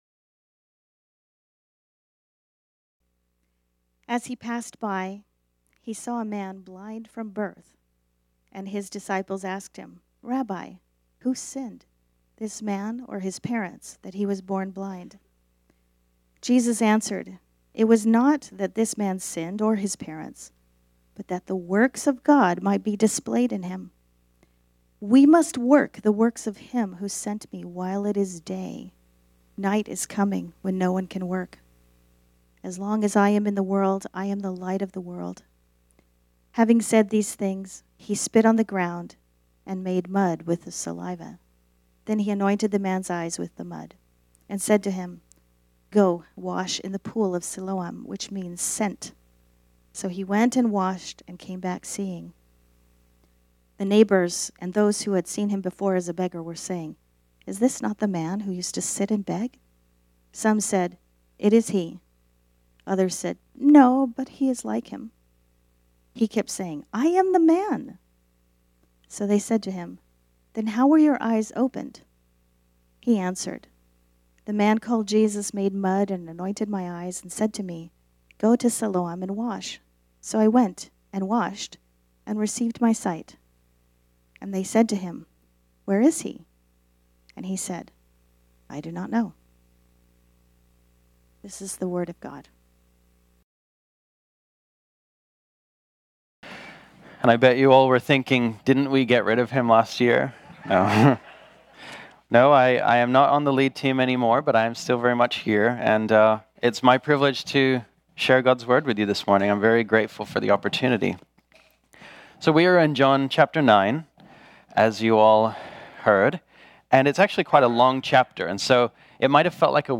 This sermon was originally preached on Sunday, January 26, 2020.